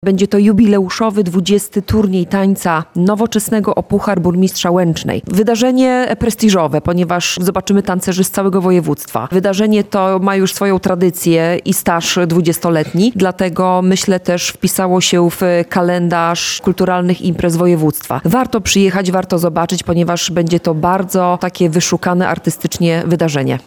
mówi w rozmowie z Radiem Lublin